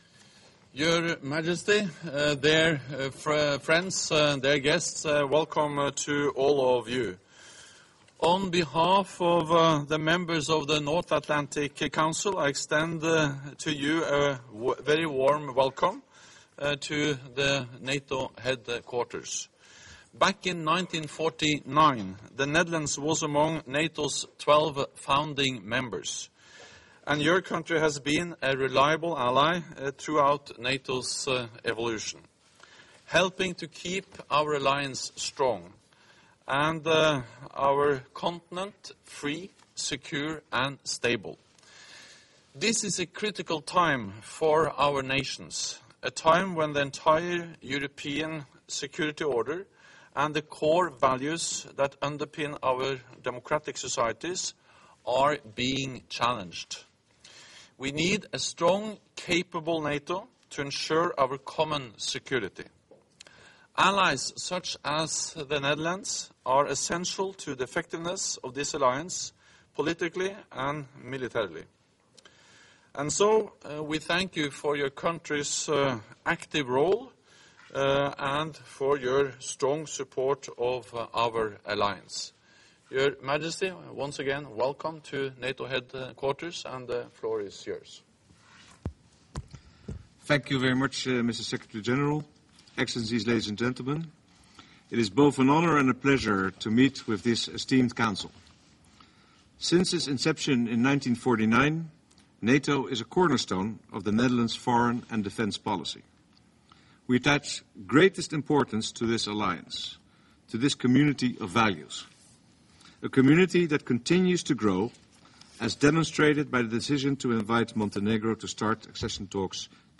Meeting of the Council with H.M. King Willem-Alexander of the Netherlands - Opening remarks by NATO Secretary General Jens Stoltenberg
(As delivered)